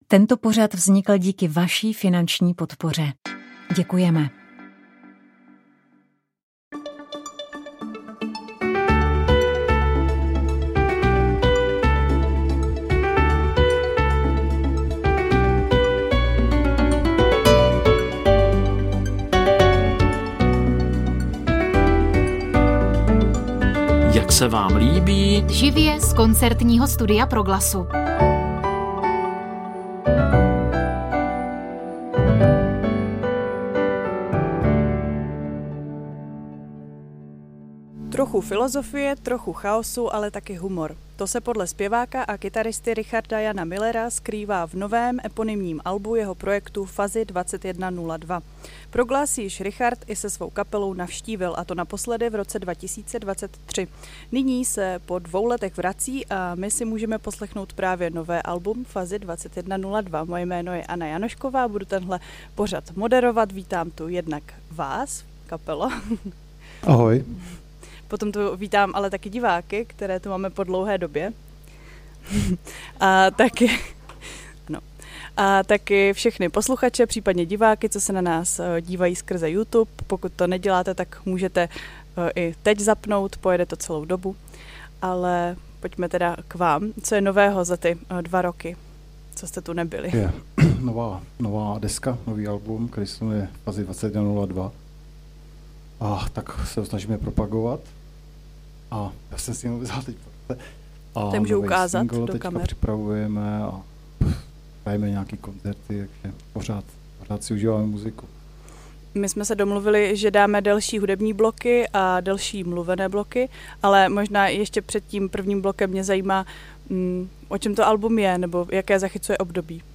Po necelém roce bude hostem pořadu Jak se vám líbí jazzový hudebník, skladatel a český průkopník jazzrockové hudby Martin Kratochvíl.